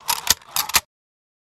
Credit Card Imprinter | Sneak On The Lot
Charge Card, Imprinter Type, Slide Style